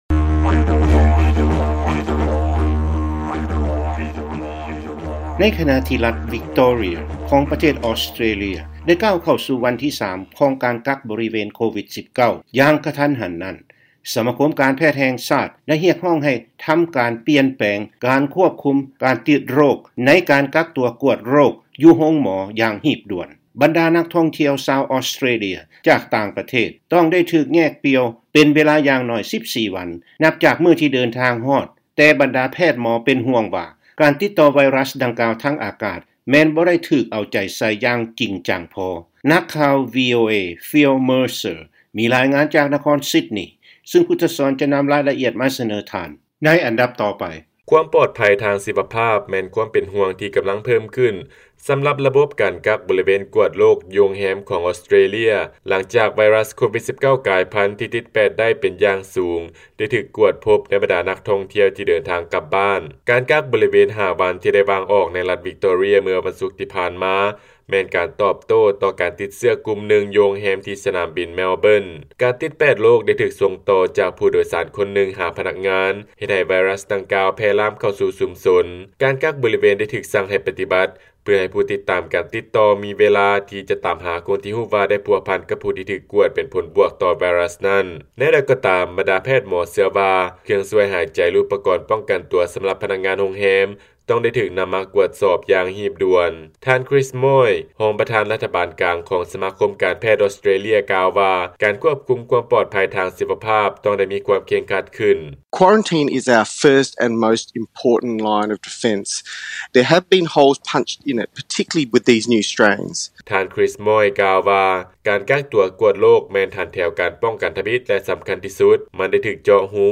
ຟັງລາຍງານ ການກັກຕົວກວດໂຣກ ຢູ່ໂຮງແຮມໃນ ອອສເຕຣເລຍ ກຳລັງຖືກກວດສອບ ໃນຂະນະທີ່ເຂົາເຈົ້າ ຮີບເລັ່ງ ເພື່ອຄວບຄຸມການລະບາດຂອງ COVID-19